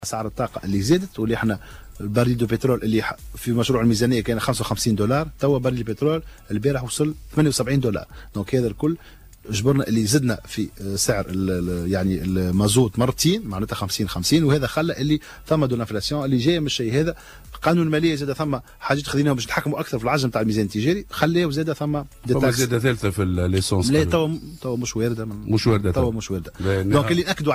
ونزل وزير التجارة اليوم ضيفا على برنامج "صباح الورد" على الجوهرة أف أم" في بث مباشر مع ستديو تونس.